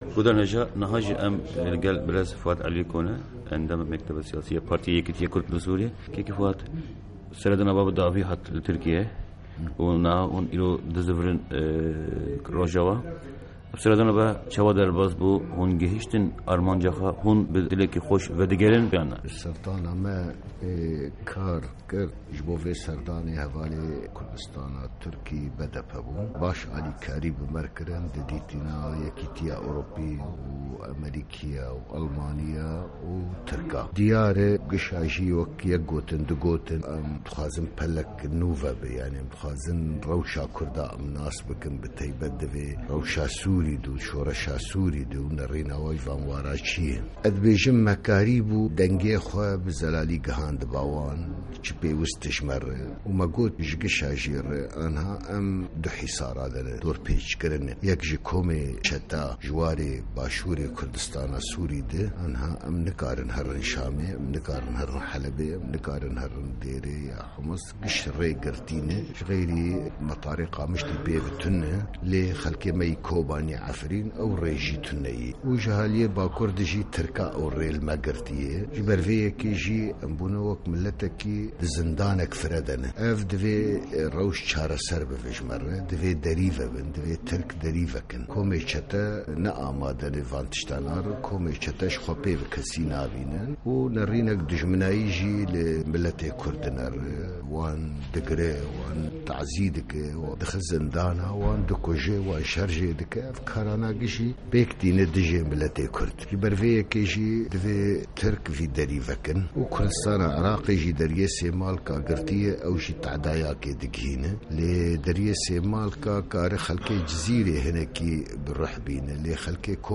Di vê hevpeyvîna taybet de